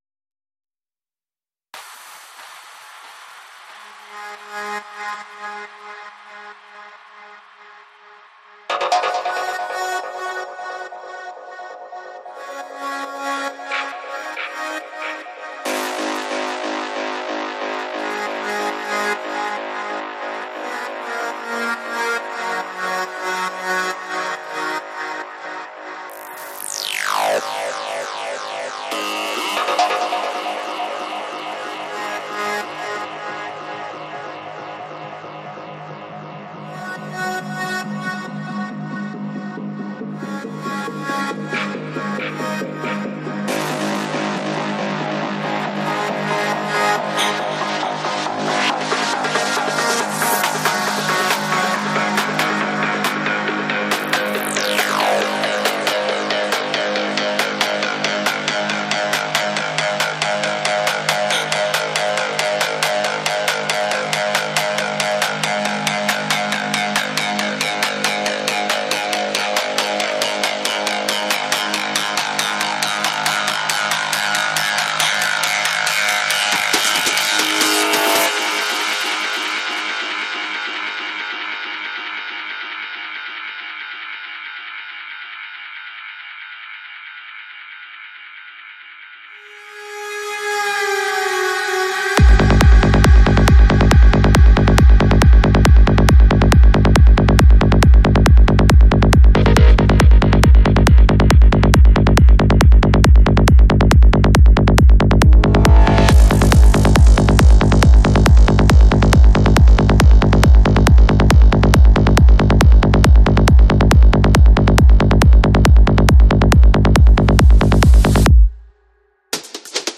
Жанр: Psychedelic